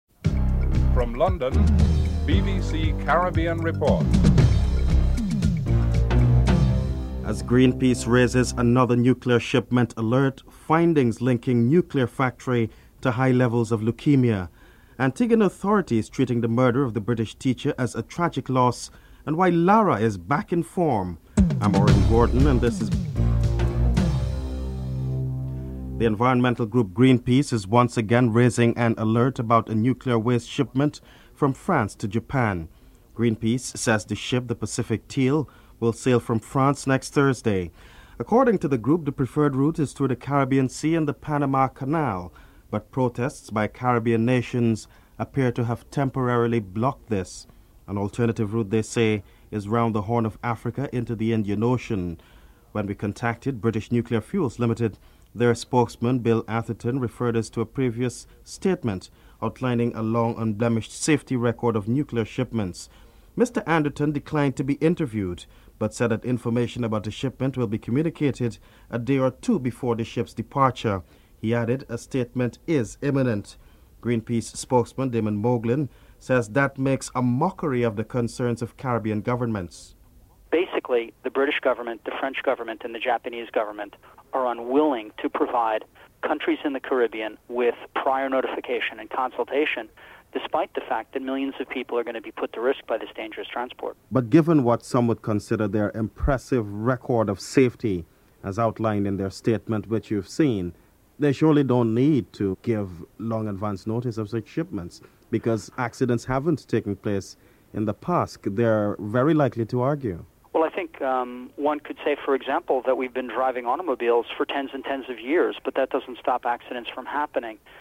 1. Headlines (00:00-00:24)
6. Canada foreign policy. Canadian Foreign Minister Lloyd Axworthy is interviewed (13:33-14:29)
7. An unbeaten century from Brian Lara. Brian Lara is interviewed (14:30-15:23)